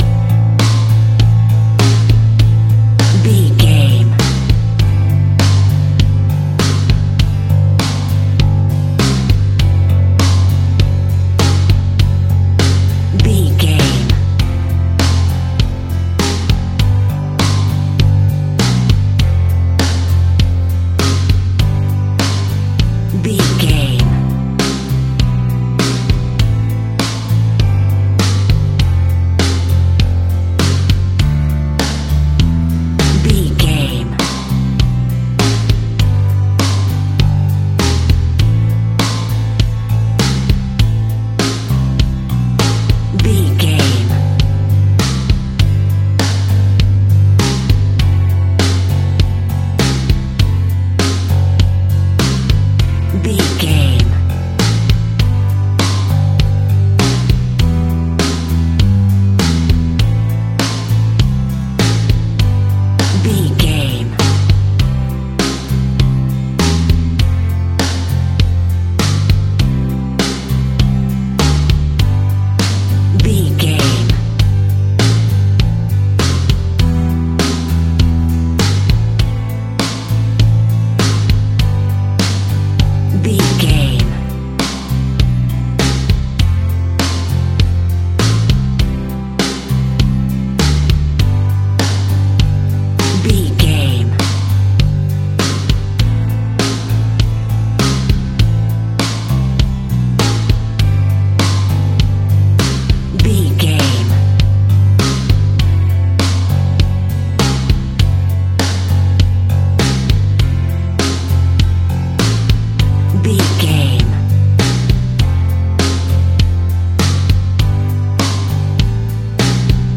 Ionian/Major
Slow
calm
melancholic
smooth
uplifting
electric guitar
bass guitar
drums
indie pop
instrumentals
organ